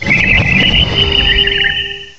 cry_not_nihilego.aif